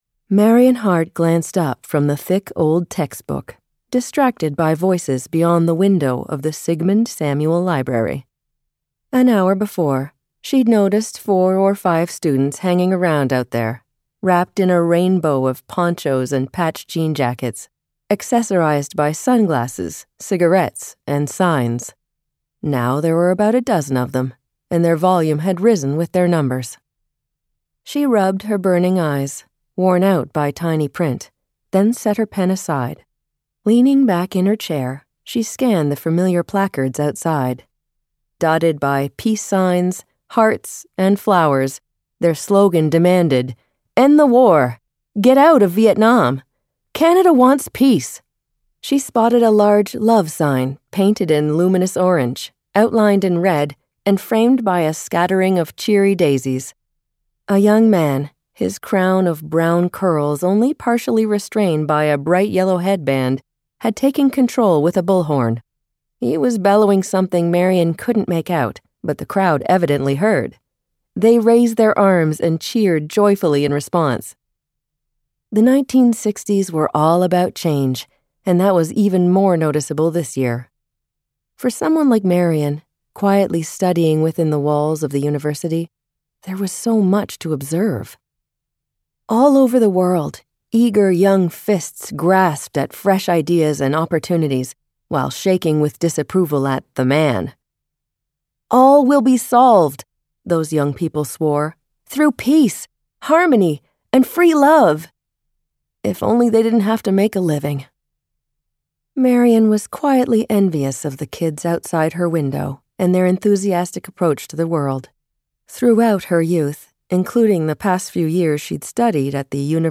Warm, flexible, seasoned, and authentic.